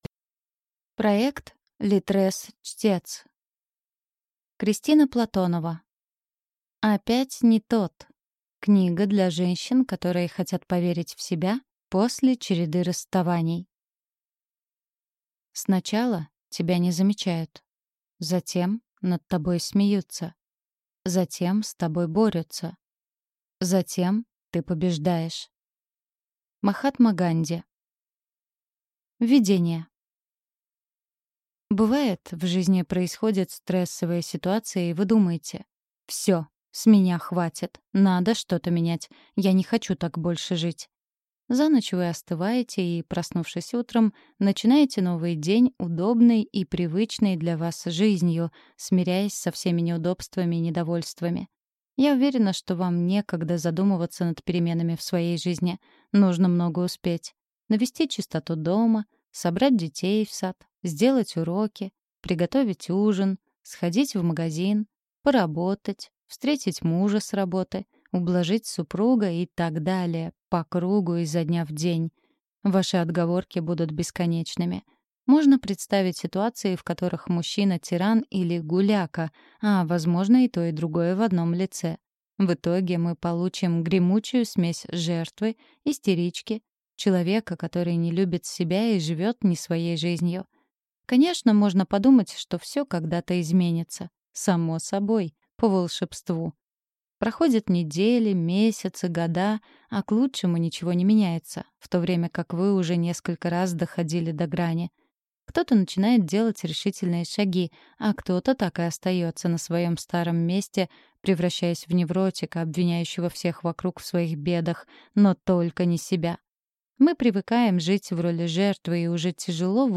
Аудиокнига Опять не тот. Книга для женщин, которые хотят поверить в себя после череды расставаний | Библиотека аудиокниг